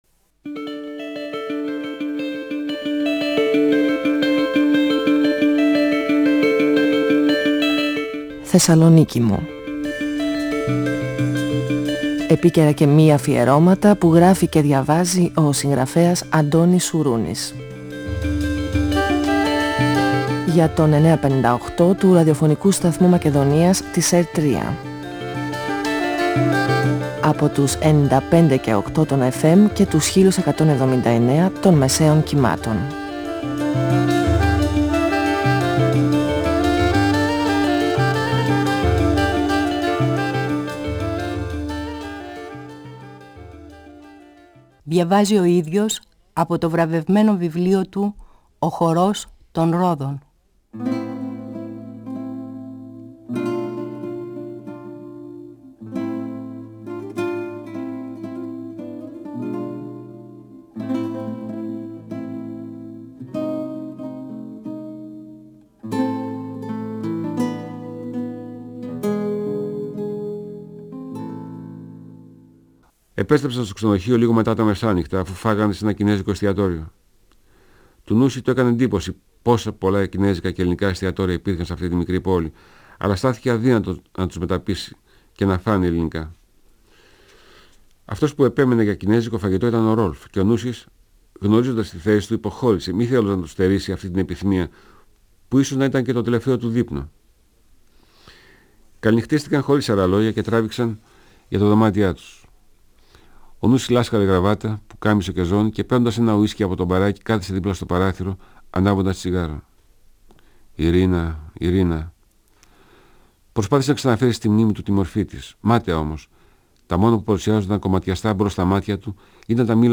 Ο συγγραφέας Αντώνης Σουρούνης (1942-2016) διαβάζει το πρώτο κεφάλαιο από το βιβλίο του «Ο χορός των ρόδων», εκδ. Καστανιώτη, 1994. Ο Νούσης επιθυμεί να συναντήσει την Ιρίνα.